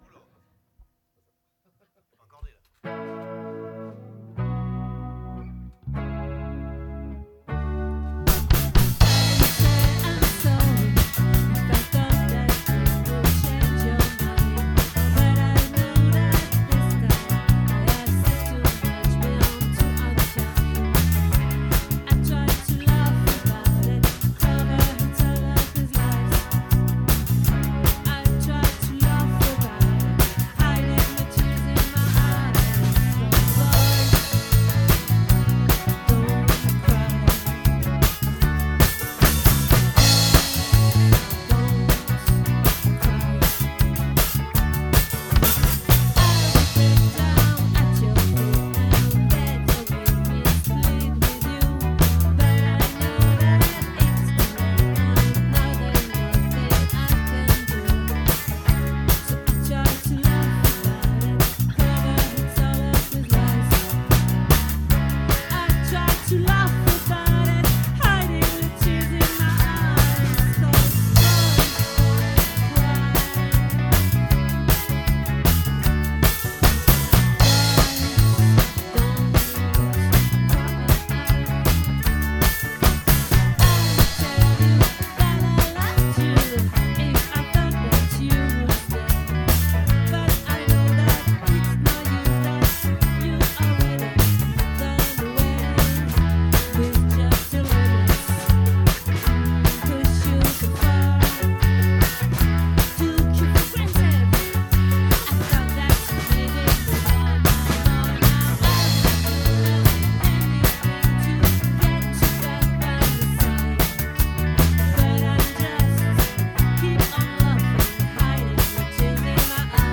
🏠 Accueil Repetitions Records_2025_12_15